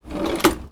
R - Foley 140.wav